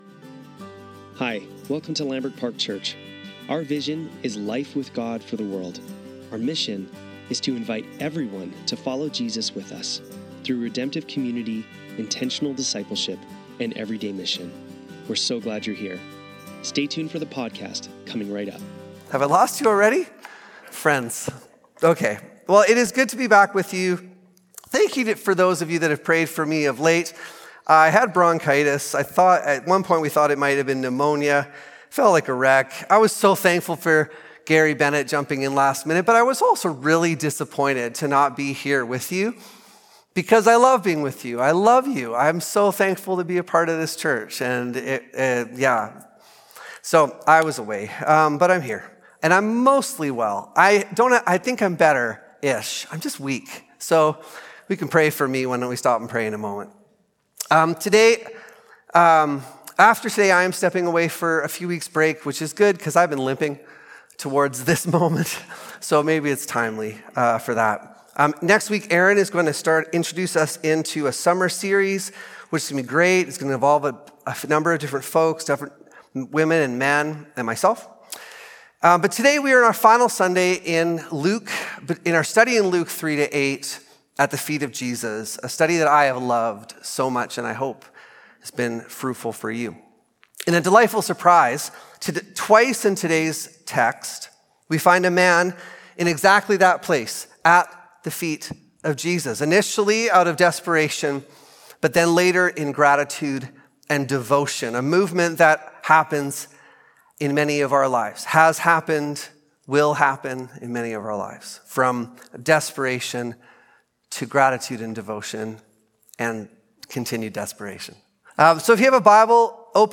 Sermons | Lambrick Park Church
Sunday Service - June 29, 2025